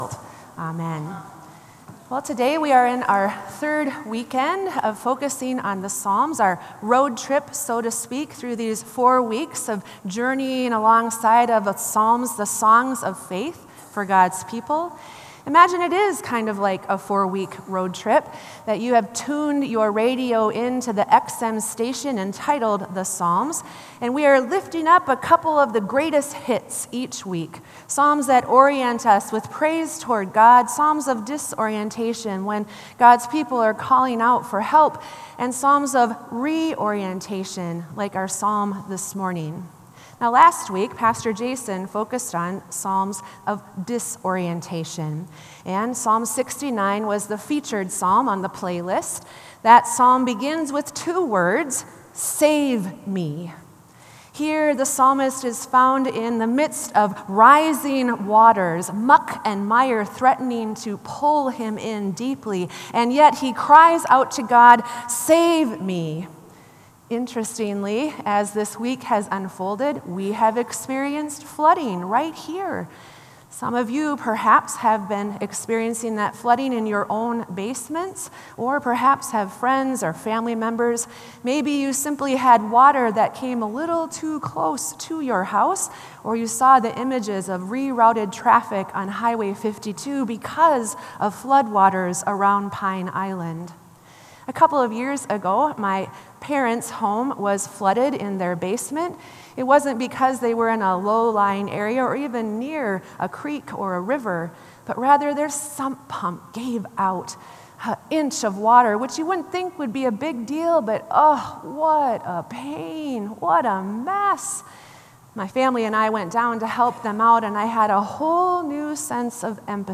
Sermon “Looking For The Light”